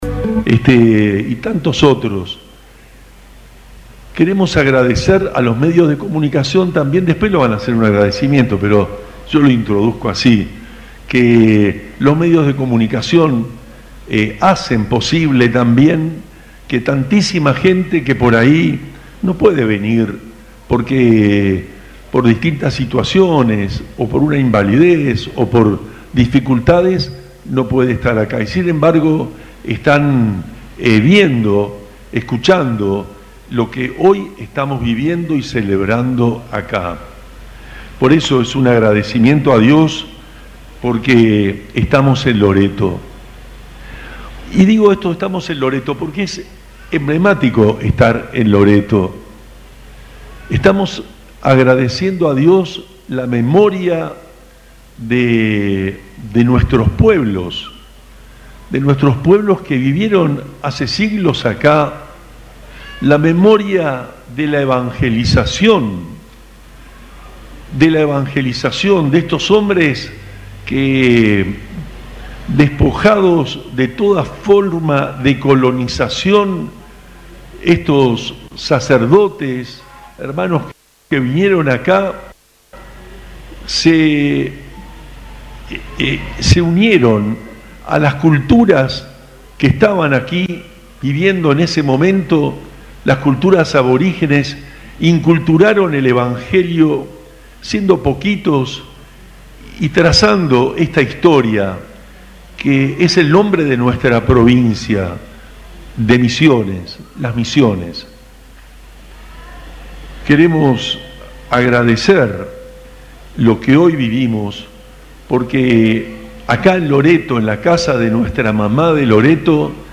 En su homilía, Monseñor Juan Rubén Martínez subrayó la relevancia de la memoria histórica, el compromiso con la misión evangelizadora y la urgencia de vivir con amor y compasión frente a un mundo marcado por el individualismo y la indiferencia.
En un emotivo encuentro de fe, miles de fieles participaron de la XXIII Peregrinación al Santuario de Loreto, donde el obispo de la Diócesis de Posadas, Monseñor Juan Rubén Martínez, presidió la Misa Central a las 9:00.